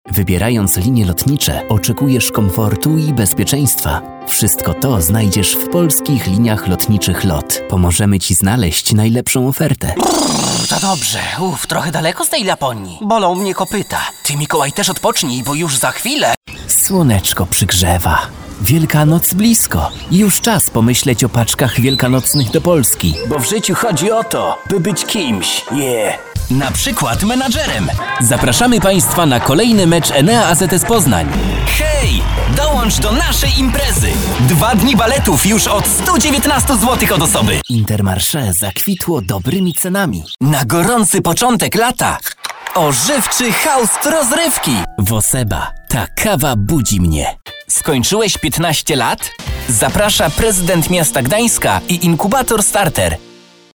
Male 20-30 lat
Young, very vital voice for commercials and other applications.
Demo lektorskie